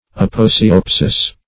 Aposiopesis \Ap`o*si`o*pe"sis\ (?; 277), n. [L., fr. Gr.